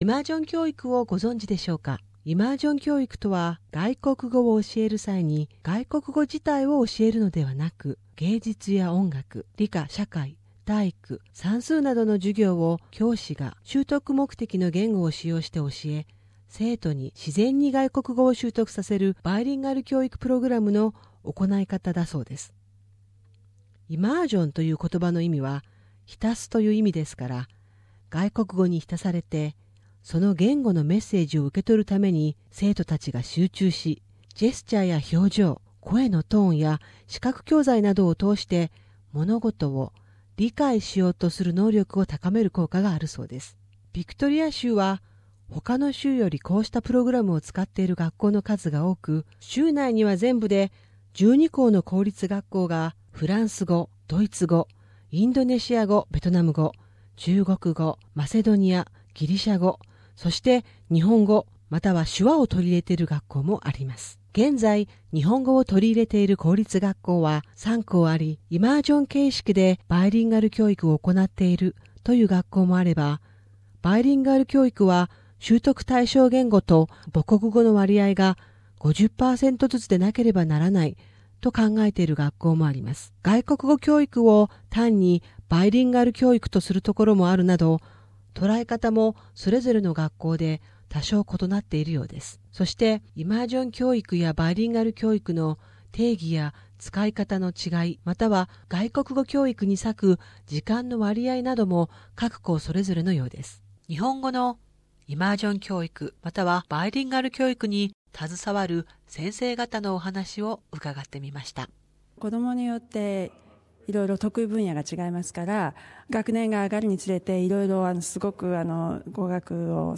携わる先生方に話を聞いた。